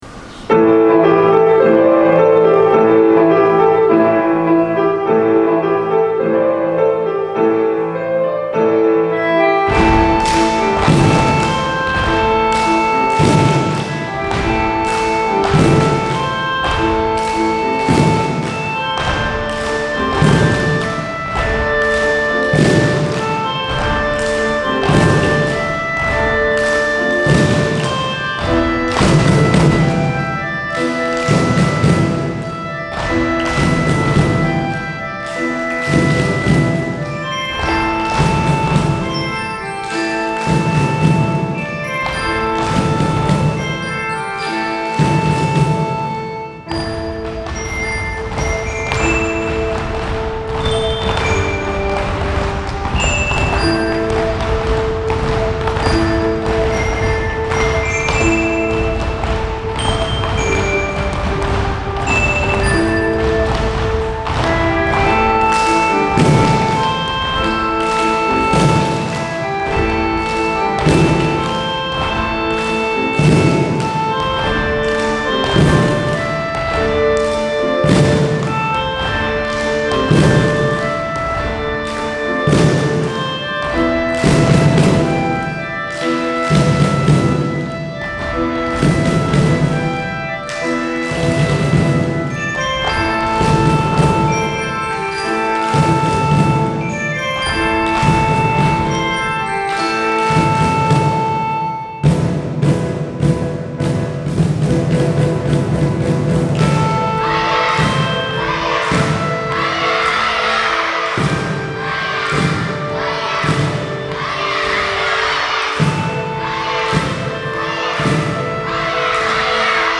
オーディションで決まった楽器とボディーパーカッションで合奏をつくります！
３パートに分かれたボディーパーカッションとカホンのリズム、グロッケンとオルガンのメロディーが重なり、元気が湧いてくる演奏になりました。